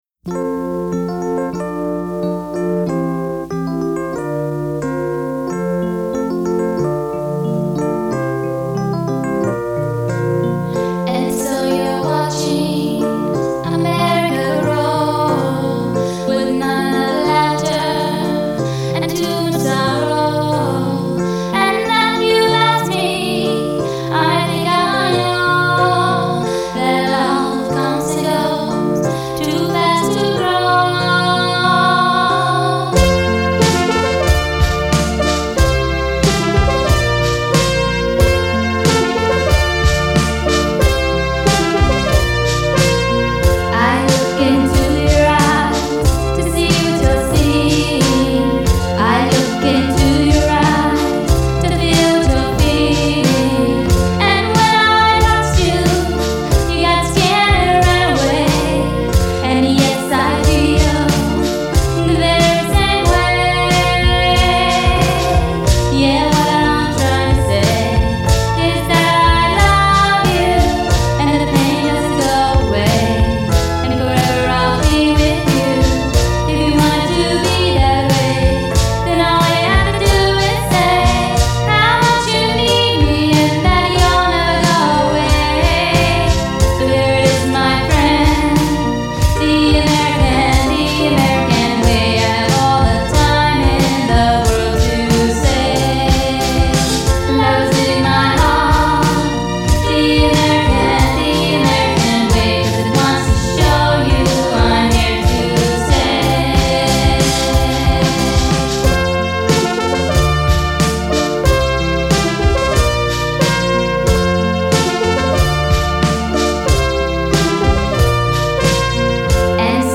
Pop/Modern